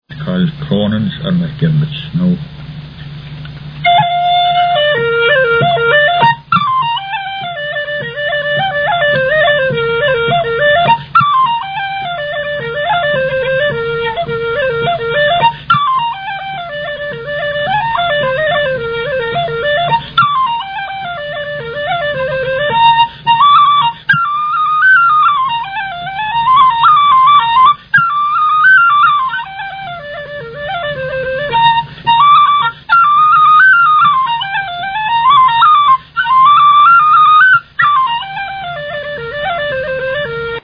a traditional reel
This recording is monaural.